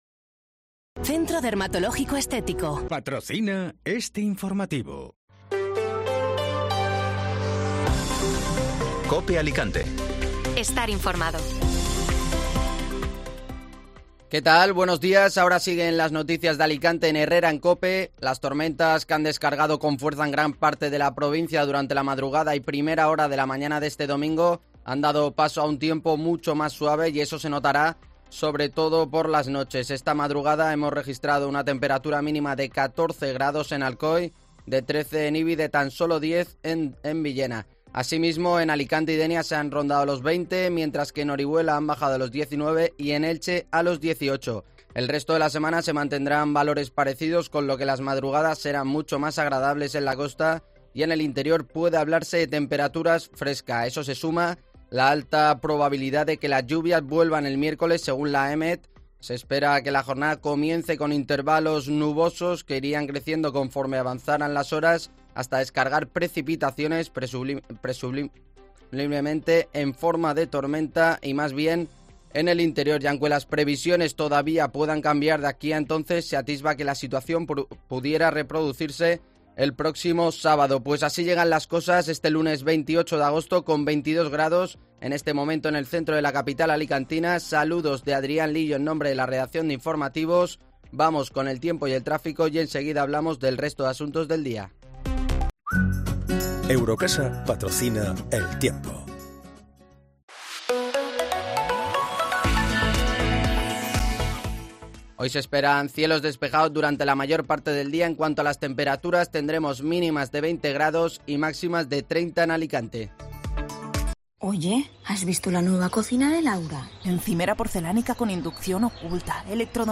Informativo Matinal (Lunes 28 de Agosto)